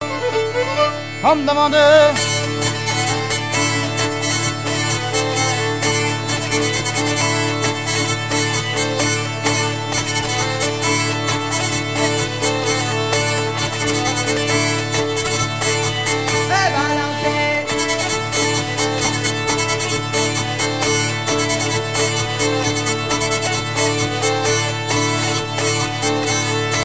Bocage vendéen
danse : branle : avant-deux
Pièce musicale éditée